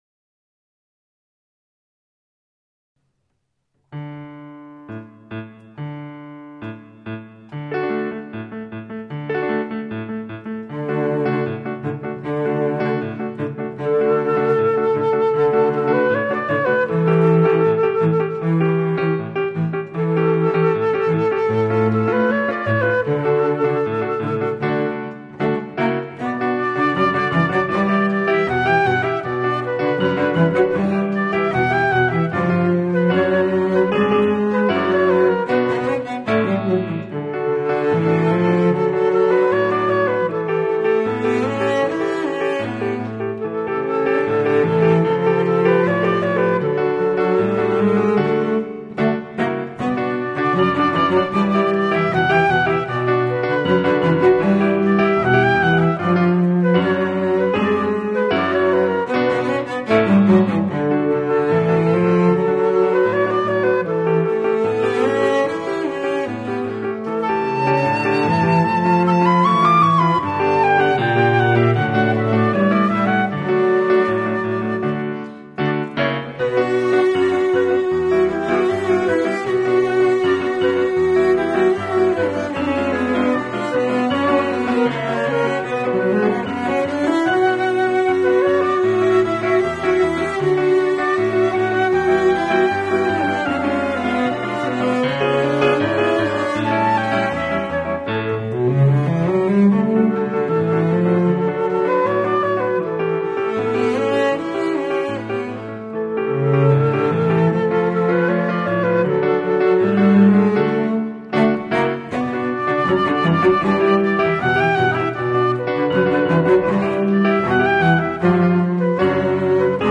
flauta, violoncello y piano